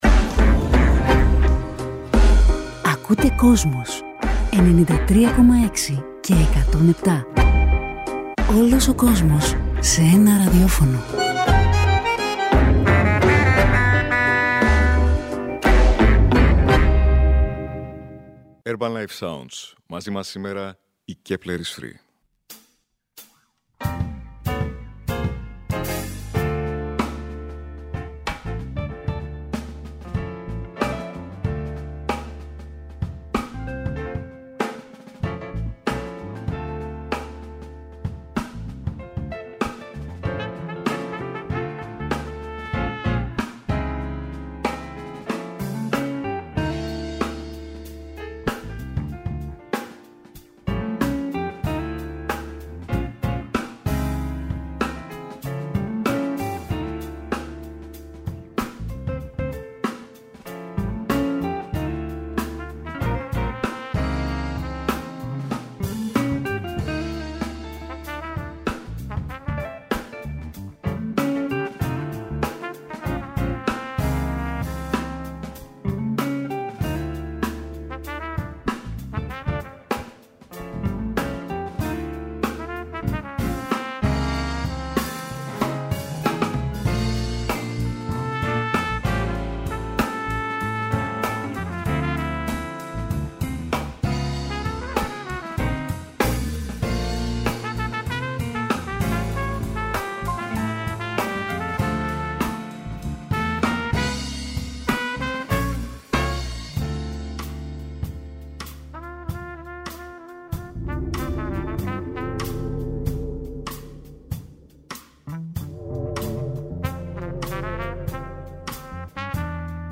έρχονται στα στούντιο της ΕΡΤ
παντρεύει το rock με την jazz
χαρίζοντάς μας ένα ξεχωριστό session